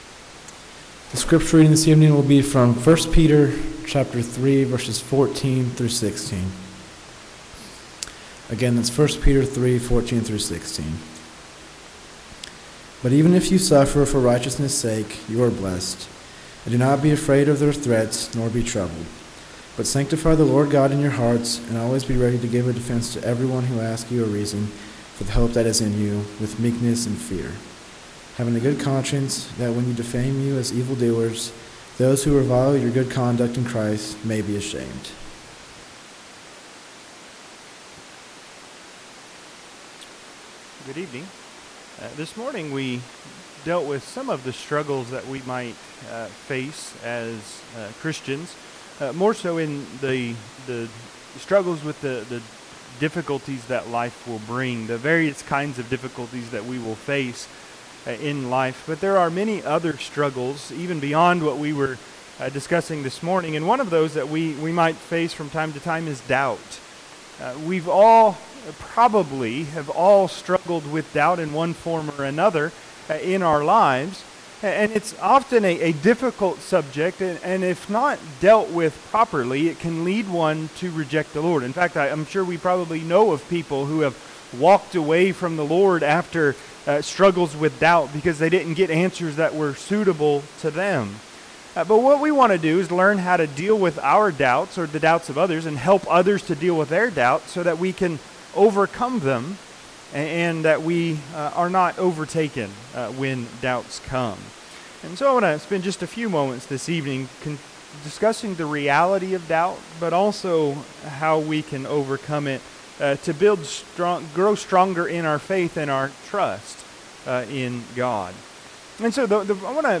1 Peter 3:14-16 Service Type: Sunday PM God can even help us with our doubts.